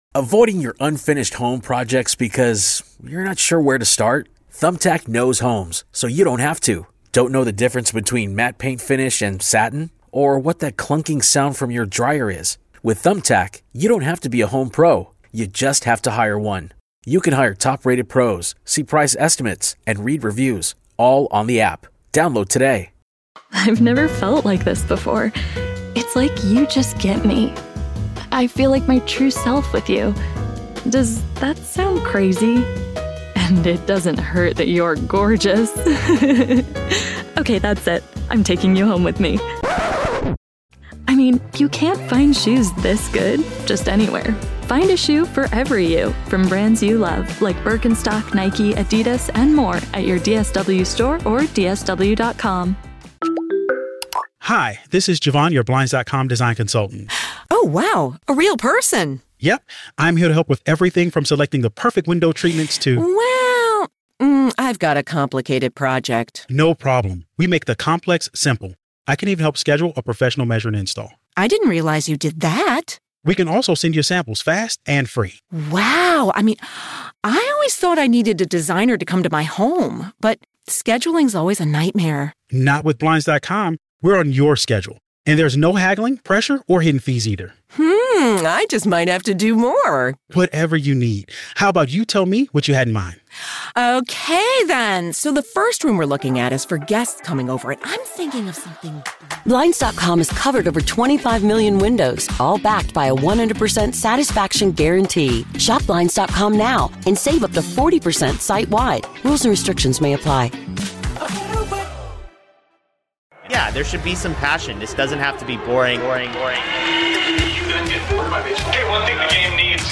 Red Sox-related audio from WEEI shows and podcasts, including postgame interviews.